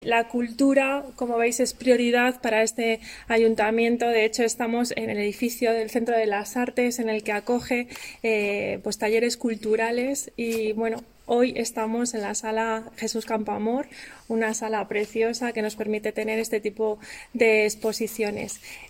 Declaraciones de la primera teniente de alcalde